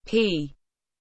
alphabet char sfx